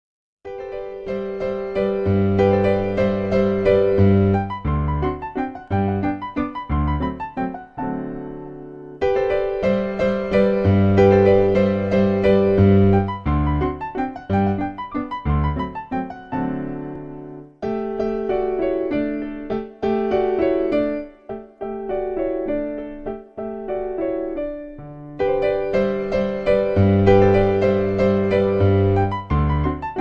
Kurz und lebendig